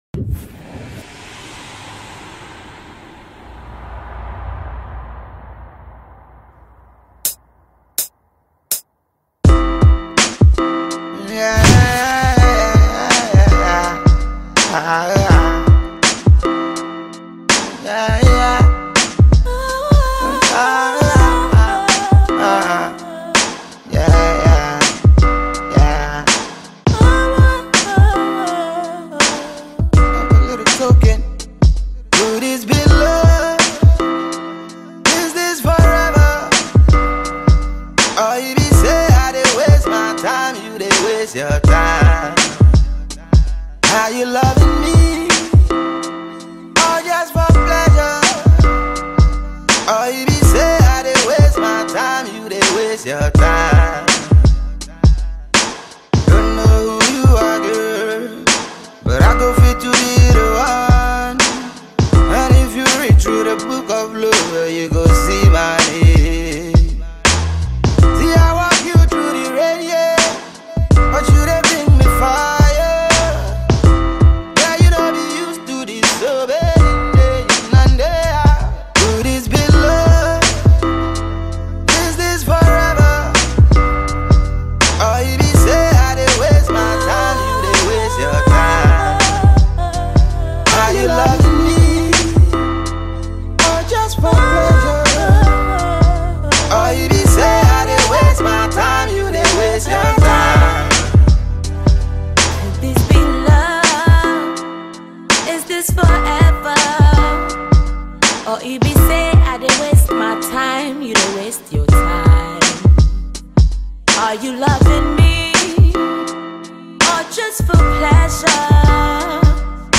The Ghanaian Music Duo
Afro pop songstress